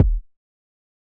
Rise Kick.wav